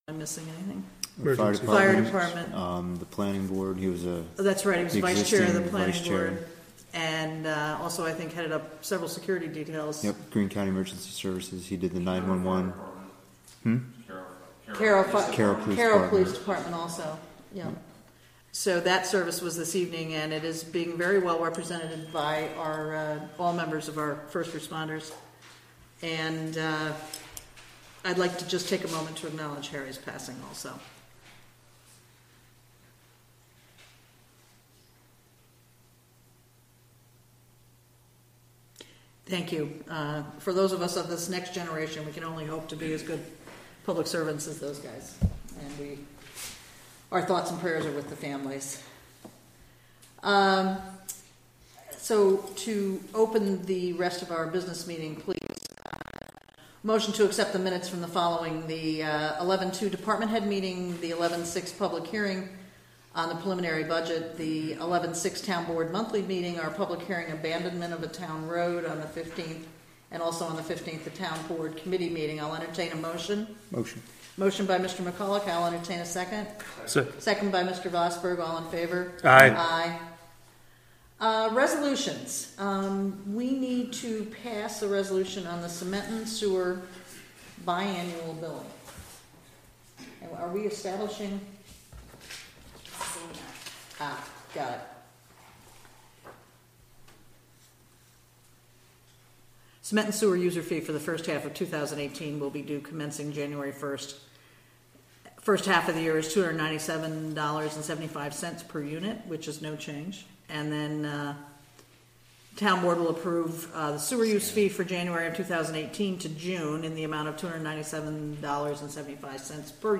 Recorded from a live webstream.